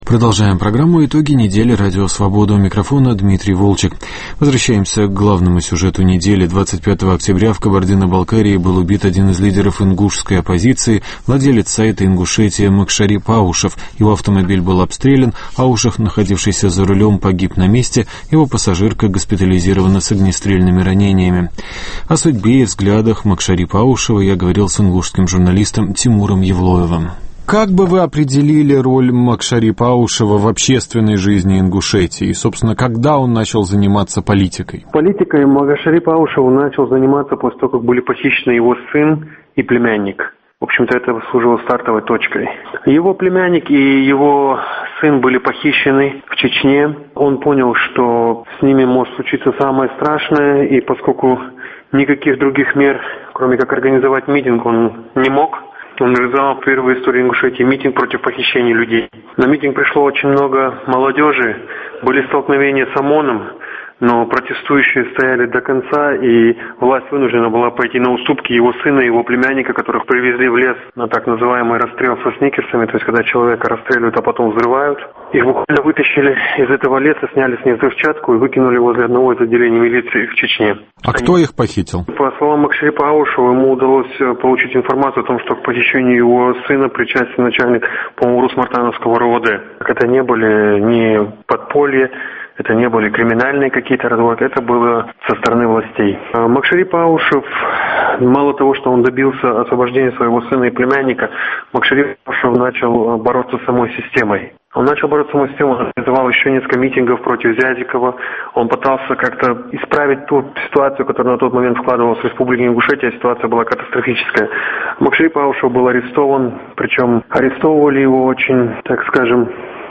Беседа с экспертами о ситуации